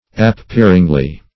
\Ap*pear"ing*ly\
appearingly.mp3